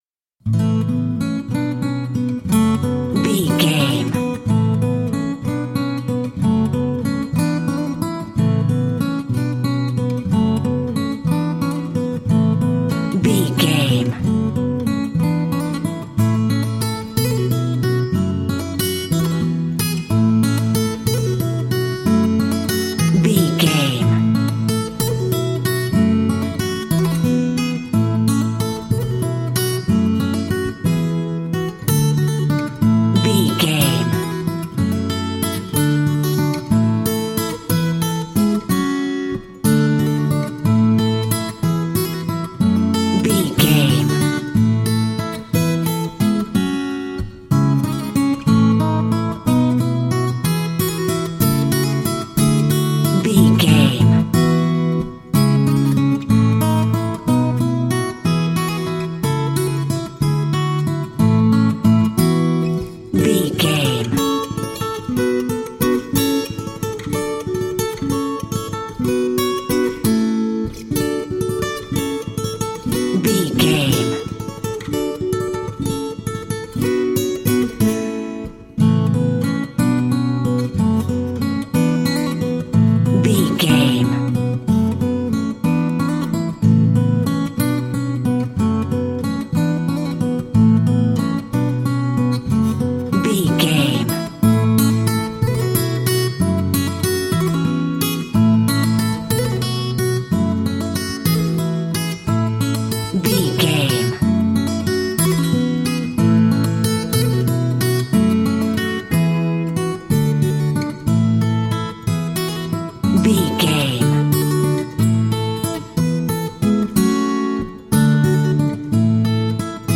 is a solo acoustic Irish/classical guitar track
Uplifting
Ionian/Major
Slow
acoustic guitar
quiet
tranquil
soft
serene